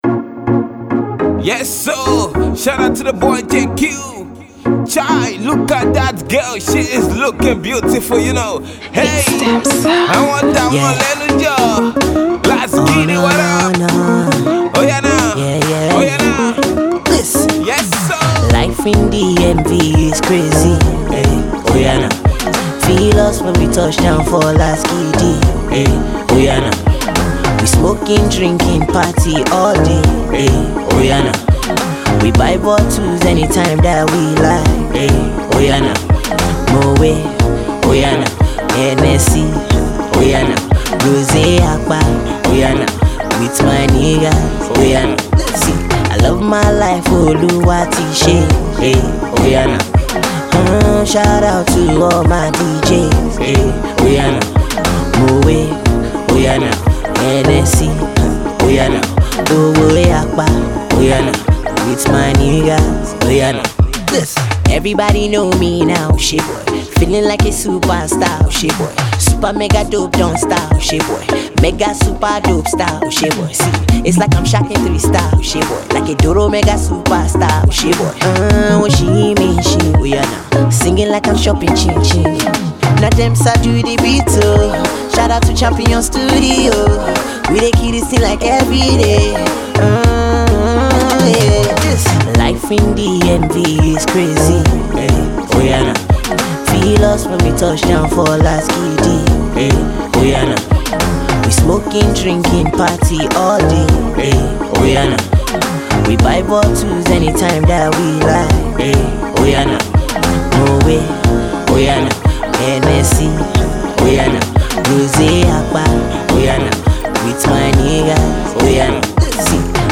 Nigerian Pop Music